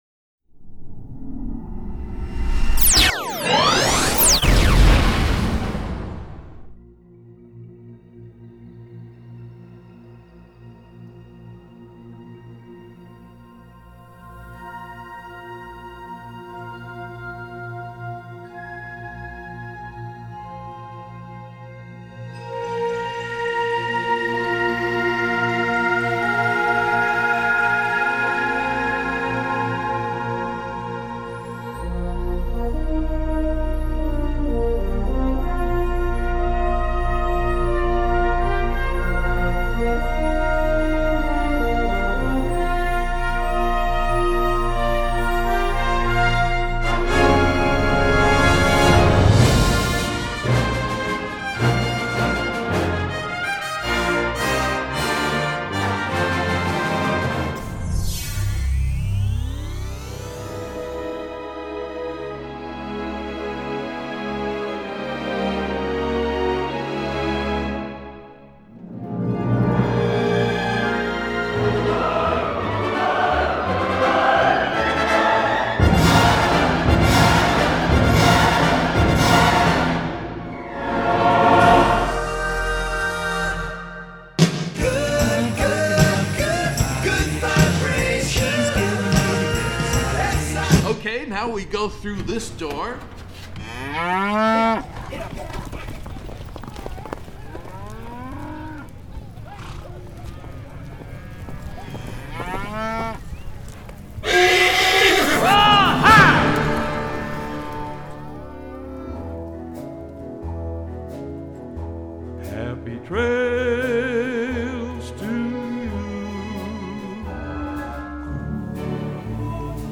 大家能从中听到不少经典影片里的配乐或片段,其中有部分片段不 适宜心脏不好的人听,嘿嘿.